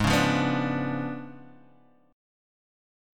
Db7b9/Ab Chord
Listen to Db7b9/Ab strummed